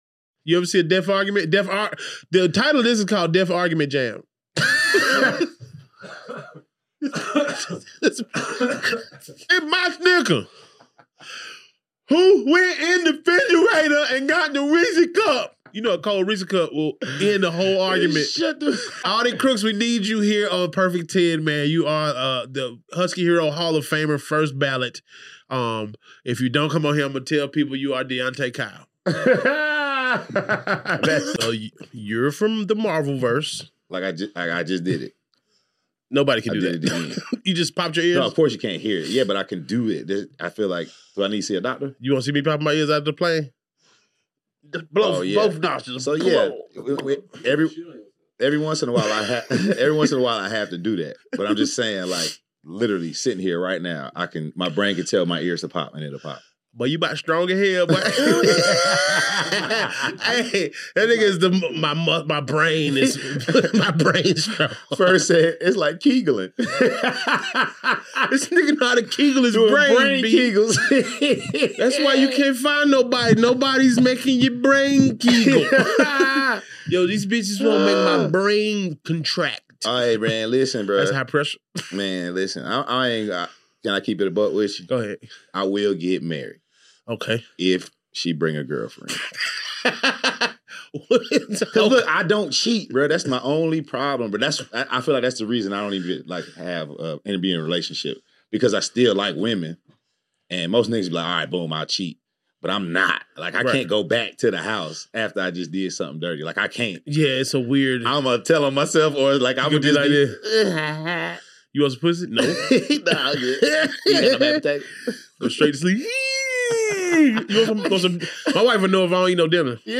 🤣 In This Video: • Hilarious commentary from Perfect 10 Guys • Internet moments that had us laughing, cringing, and reacting in real time • The best viral reactions from the internet 💬 Let us know in the comments: What part made you laugh the hardest?